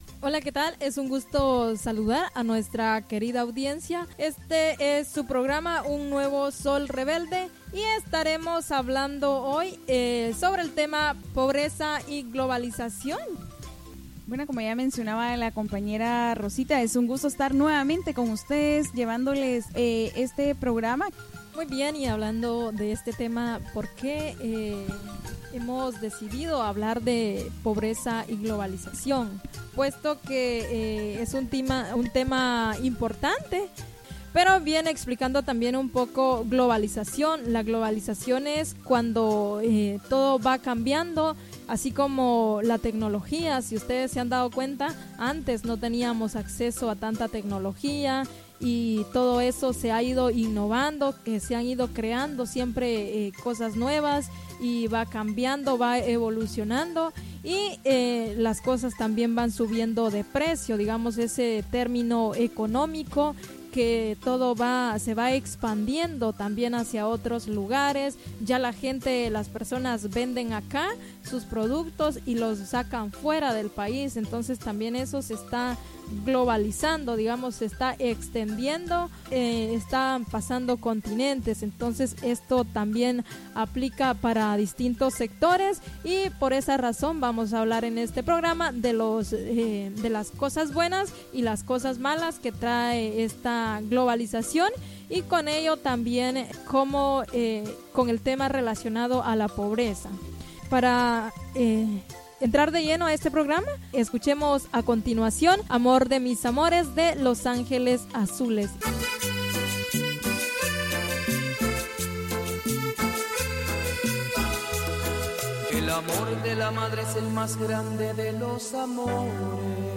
Un Nuevo Sol Rebelde te invita escuchar su programa radial Pobreza y Globalización, incluye entrevista, música, derechos, alternativas.